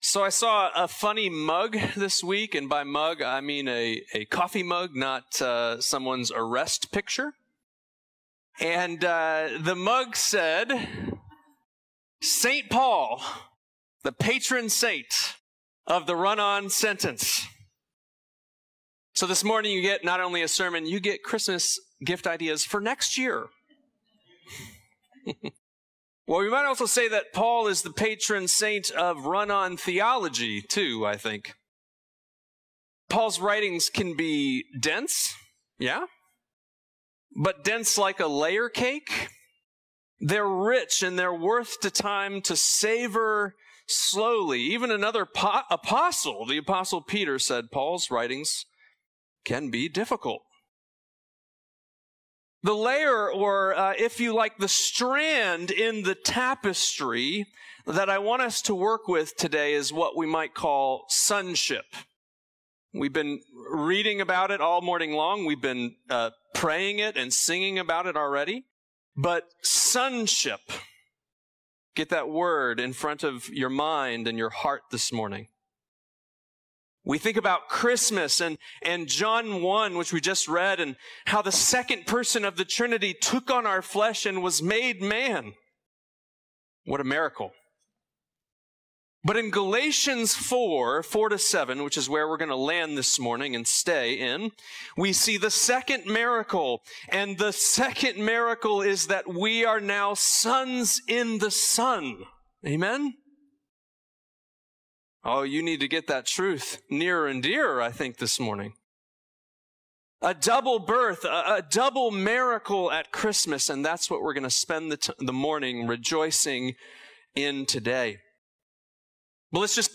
Sermons - Holy Cross Anglican Cathedral